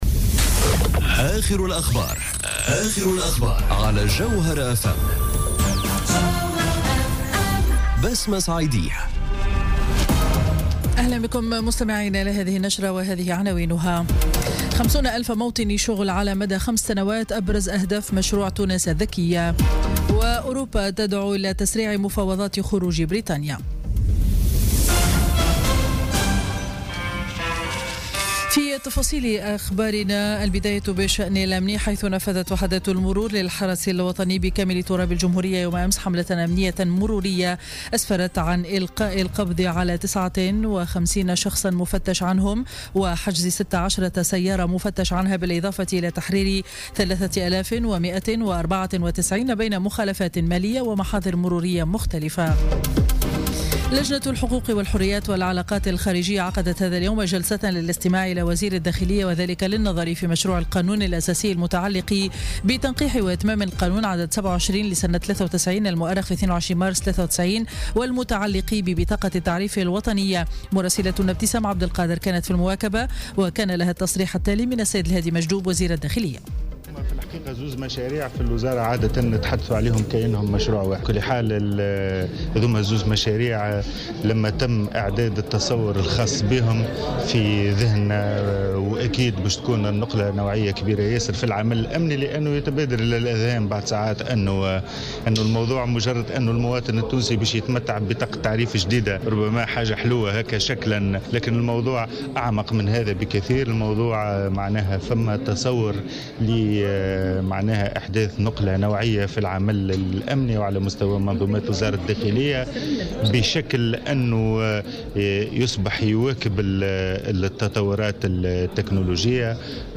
نشرة أخبار منتصف النهار ليوم الجمعة 9 جوان 2017